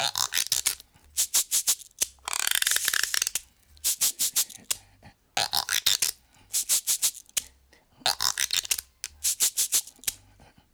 88-PERC-04.wav